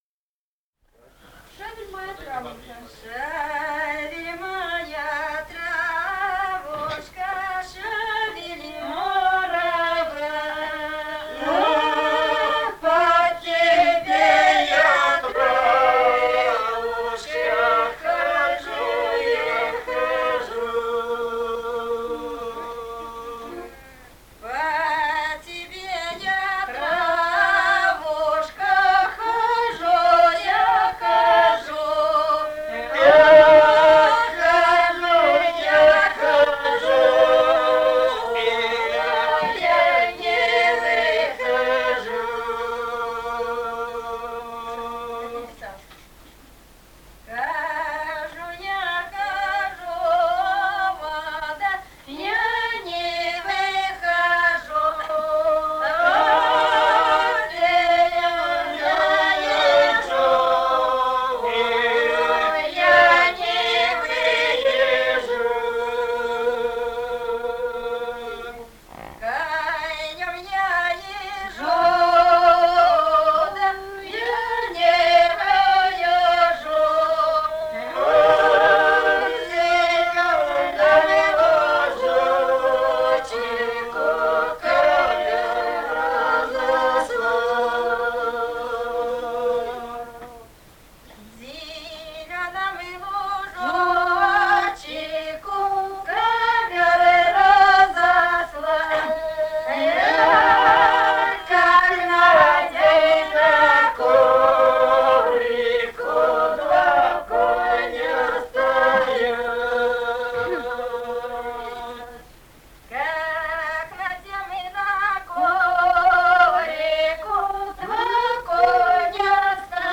Этномузыкологические исследования и полевые материалы
Грузия, с. Родионовка (Паравани), Ниноцминдский муниципалитет, 1971 г. И1311-38